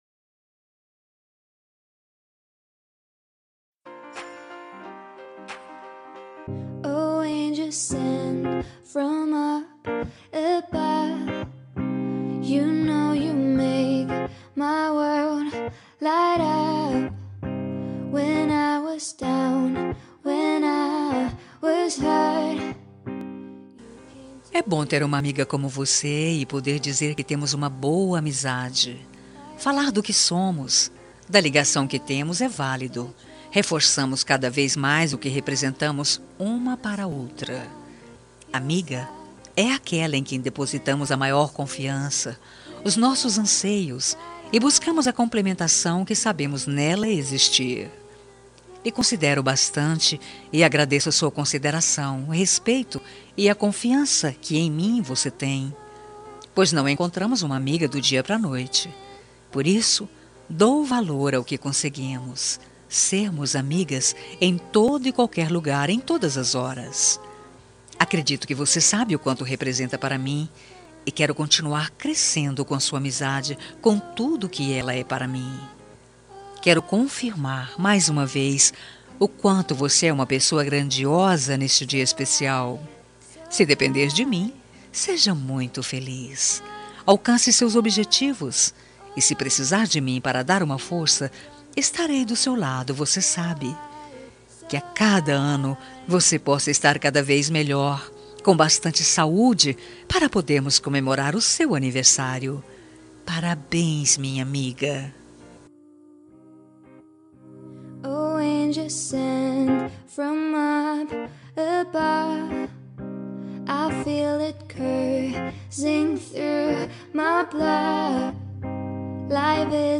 Telemensagem Aniversário de Amiga – Voz Feminina – Cód: 201818